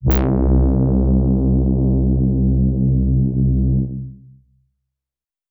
Lush Pad C3.wav